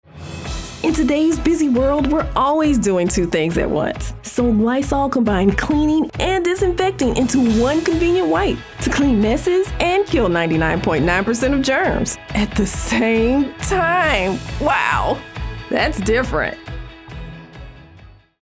Female
Yng Adult (18-29), Adult (30-50)
My voice is warm, confident, friendly, and versatile, adapting to the needs of each project.
Radio Commercials
Words that describe my voice are Friendly, Relatable, Casual.